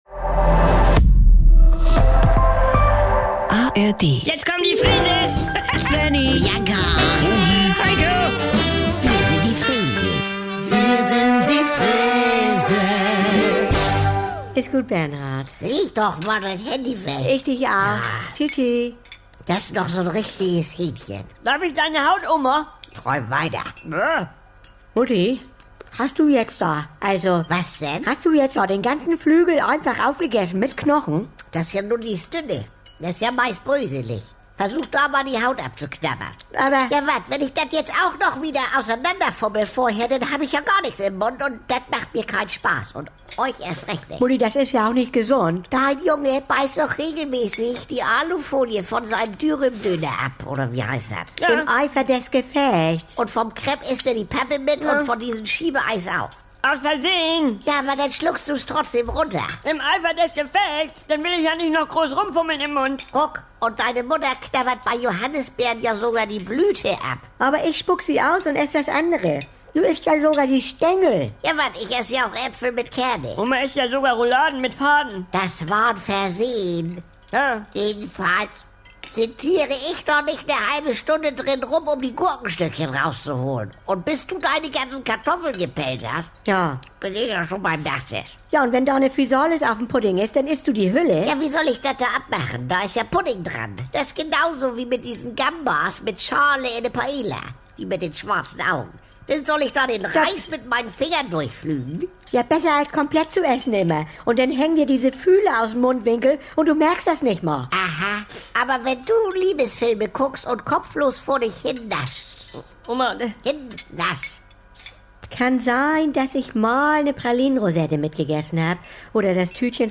Saubere Komödien NDR 2 Komödie Unterhaltung